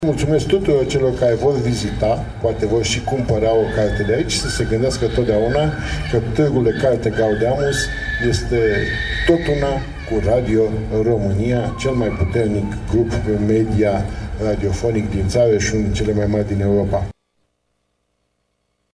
Georgică Severin, președintele Societății Române de Radiodifuziune: